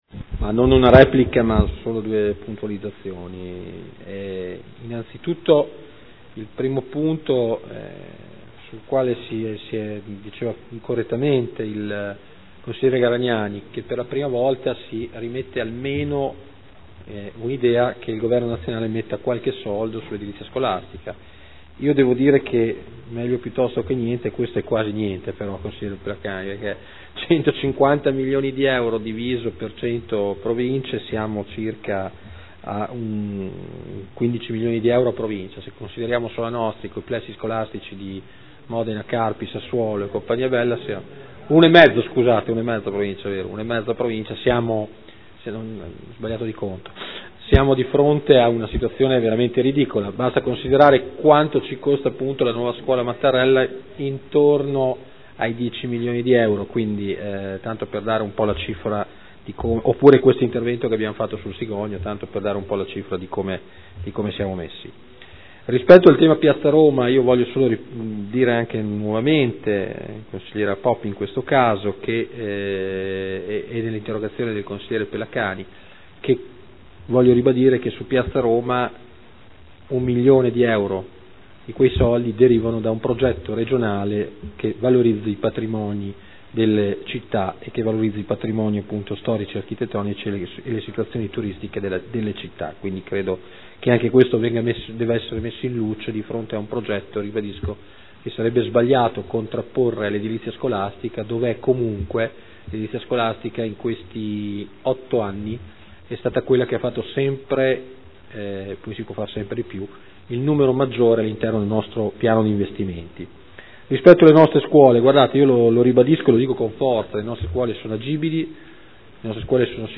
Marino Antonino — Sito Audio Consiglio Comunale